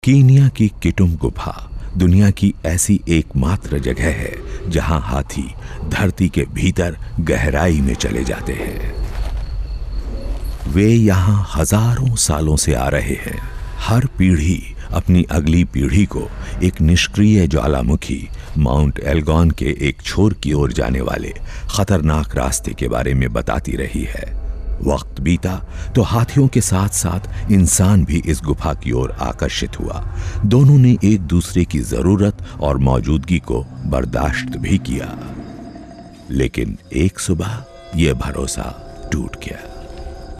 My voice ranges from middle aged to senior.
Deep, warm, soft, soothing, smooth, voice.
Sprechprobe: Werbung (Muttersprache):